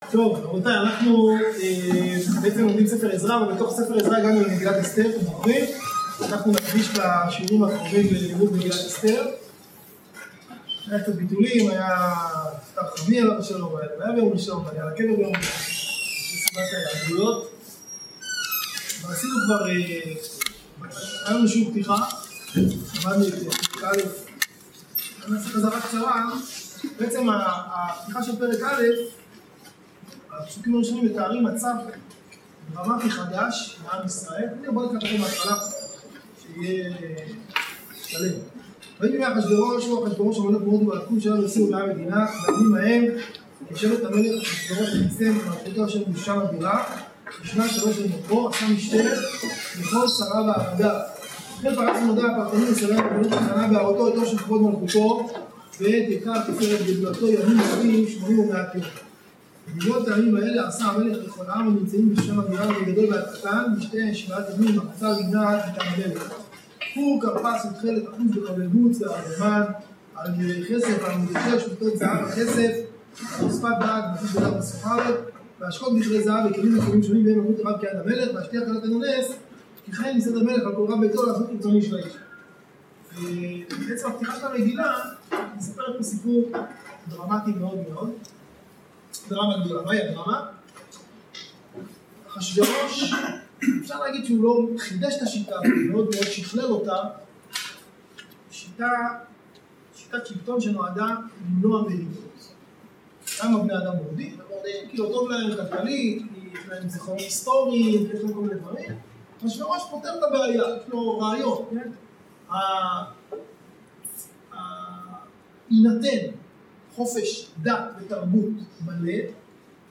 *בגלל תקלה איכות ההקלטה לא טובה